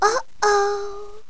genie_die4.wav